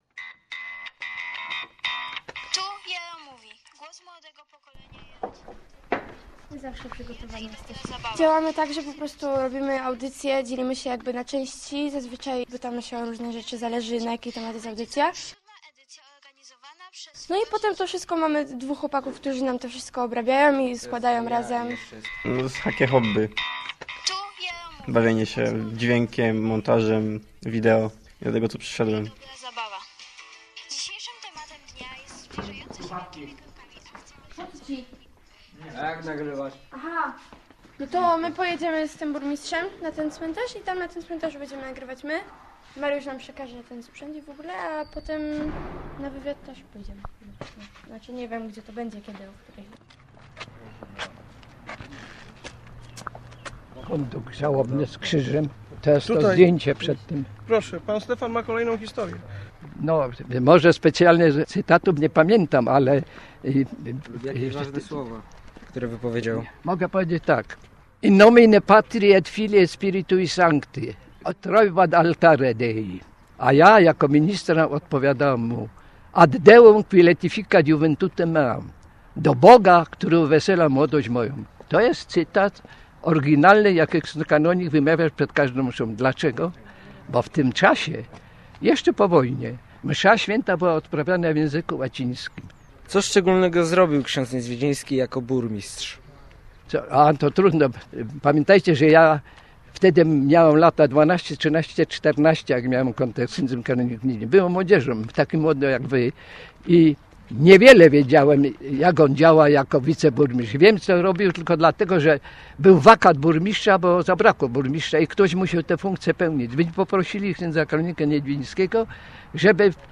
W audycji wykorzystano wspomnienia ludzi, którzy dobrze pamiętają tę postać.
qey8sjy0a35j5hu_reportaz_ksiadz-burmistrz.mp3